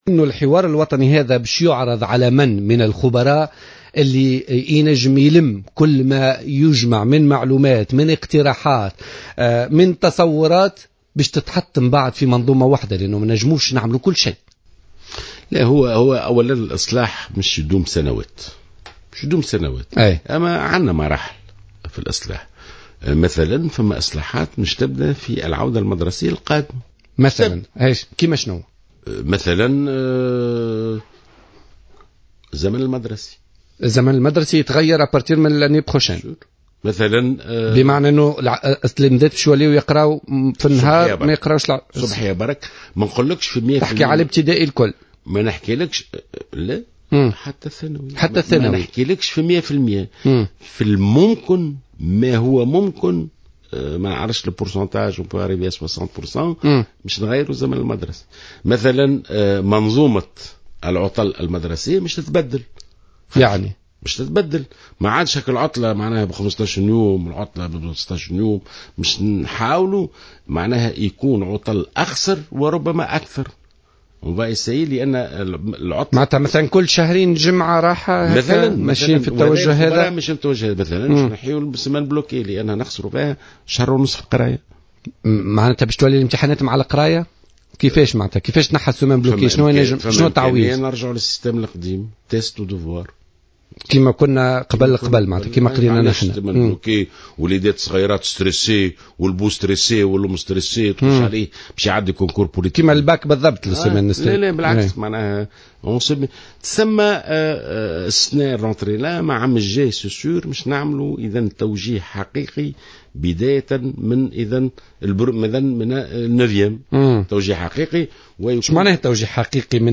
Dans le cadre des réformes du système éducatif, les horaires de classe vont changer à partir de la prochaine rentrée de sorte que les cours soient dispensés le matin. C’est ce qu’a affirmé le ministre de l’Education, Néji Jalloul, lors de l’émission Politika de ce lundi 18 mai 2015 sur Jawhara Fm.